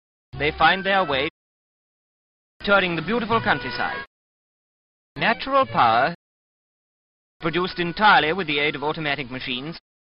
Here from an old Pathé newsreel is an assortment of old-fashioned centring diphthongs – “they find th[eə] way… t[ʊə]ring the beautiful countryside… natural p[ɑə]… produced ent[aə]ly with the aid of automatic machines”: